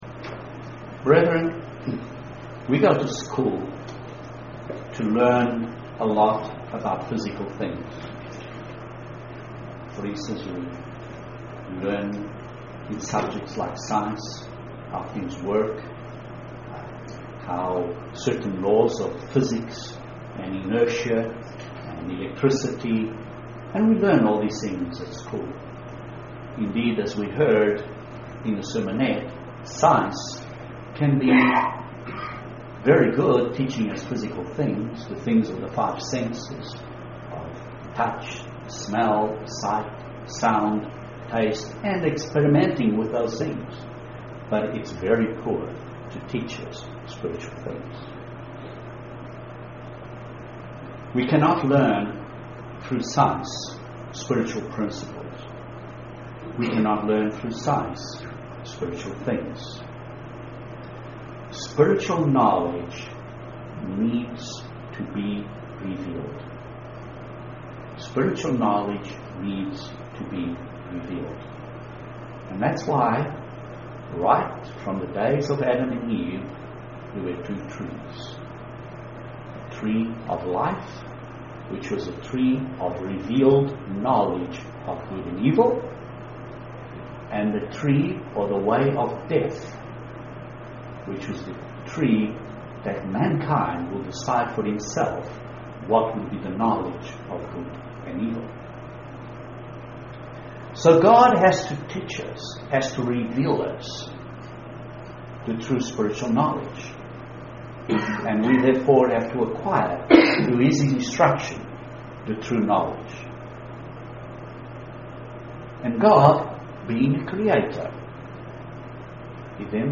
Blessings of water teach us lessons of spiritual blessings of the Holy Spirit UCG Sermon Transcript This transcript was generated by AI and may contain errors.